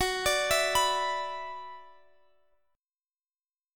Listen to F#7sus4#5 strummed